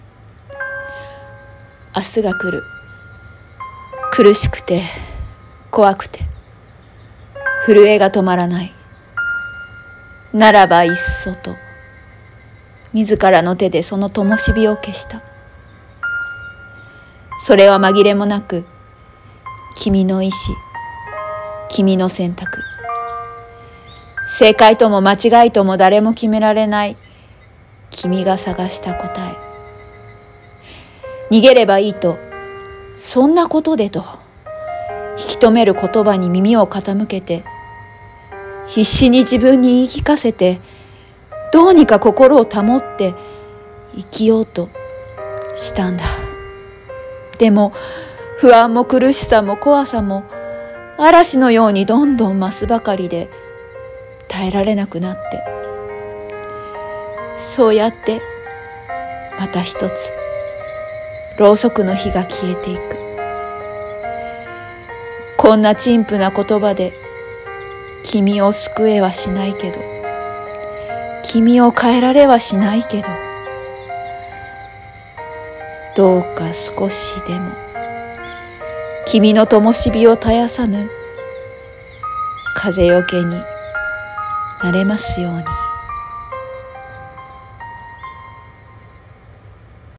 朗読